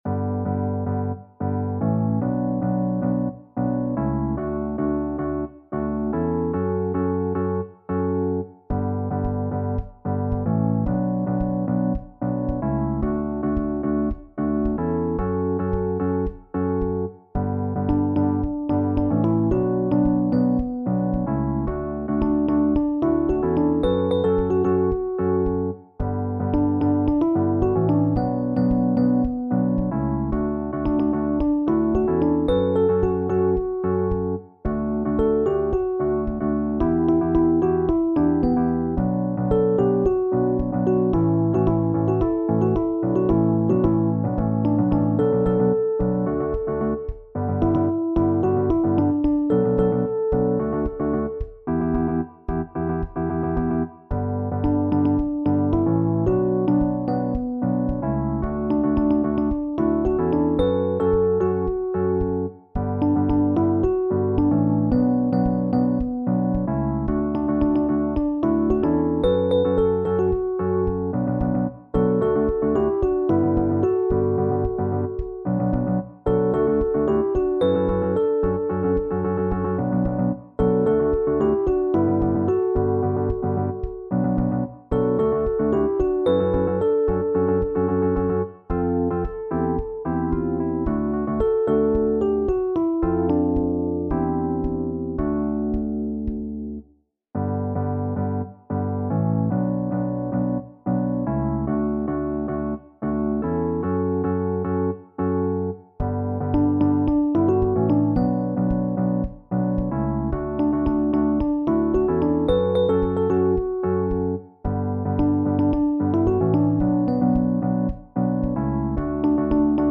SATB met solo | SSATB | SSA met solo